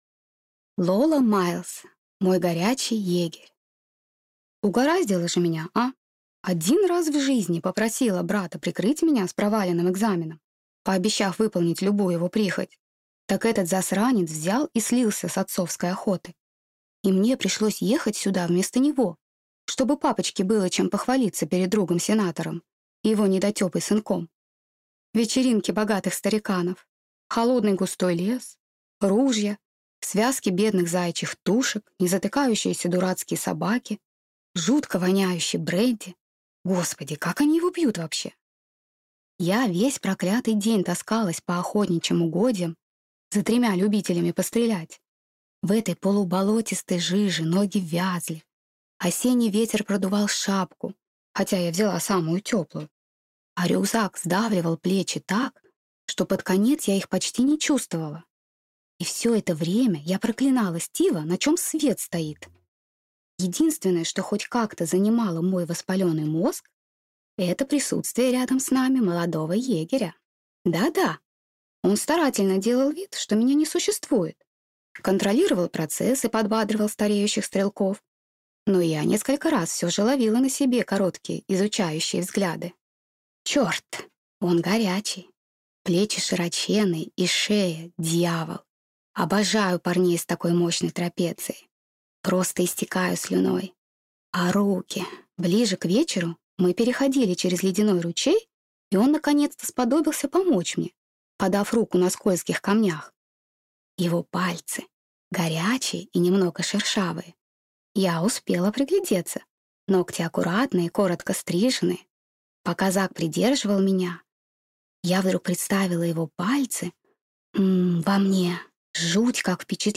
Аудиокнига Мой горячий егерь | Библиотека аудиокниг
Прослушать и бесплатно скачать фрагмент аудиокниги